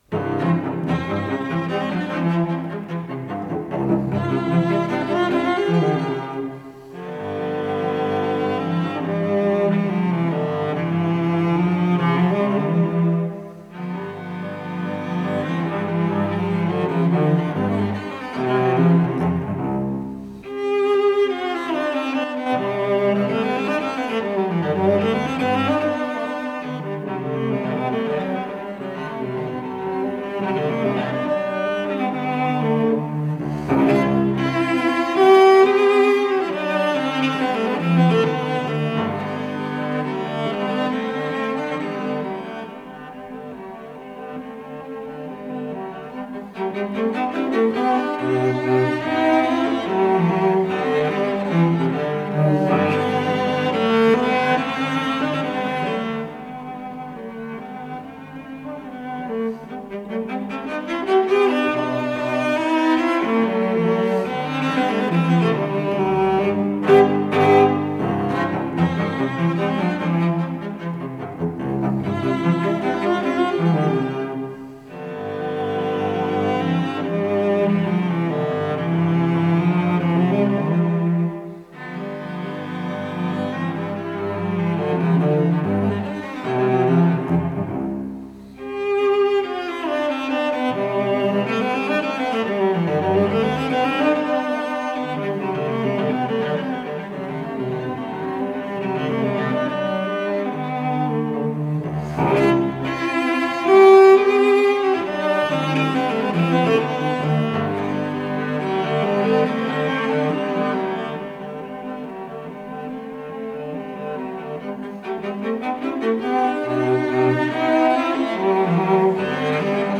с профессиональной магнитной ленты
ФондНорильская студия телевидения (ГДРЗ)
Аллегро модерато
ВариантДубль моно